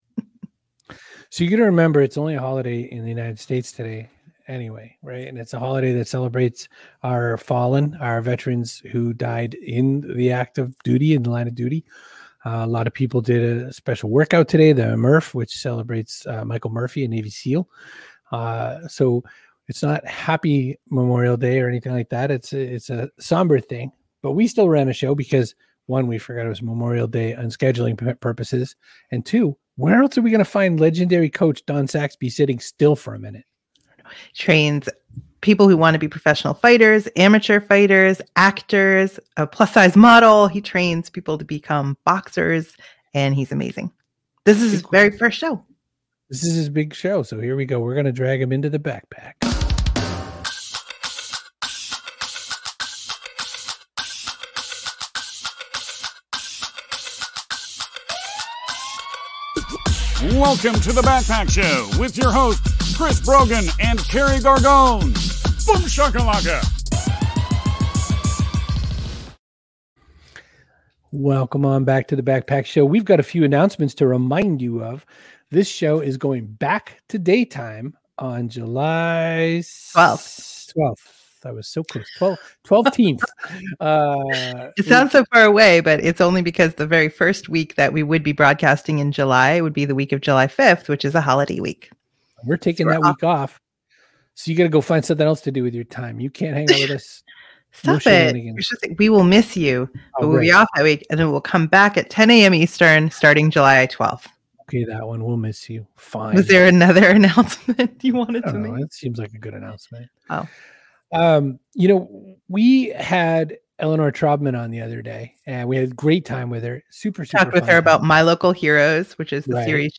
**NOTE: This episode had tough connection issues. Apologies.** &nbsp